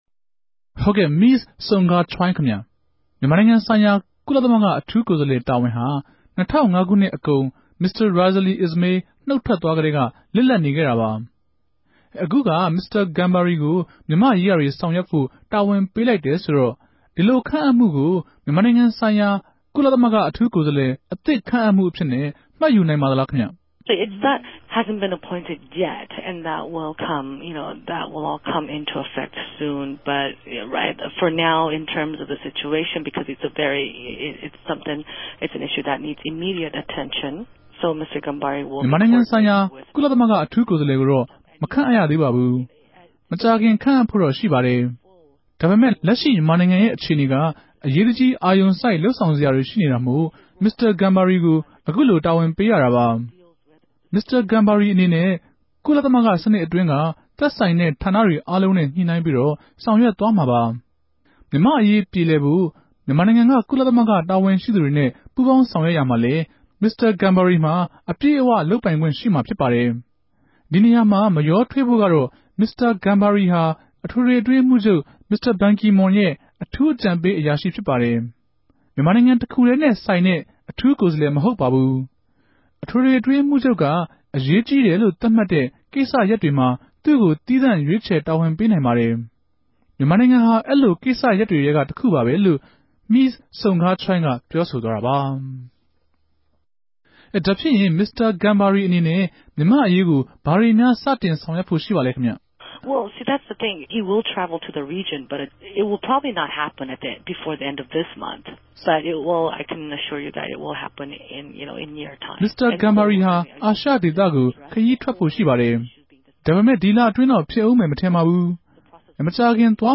တြေႚဆုံမေးူမန်းထားပၝတယ်၊၊